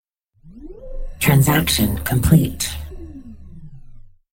new transaction voice ui
transaction-complete-female-spark-informative.mp3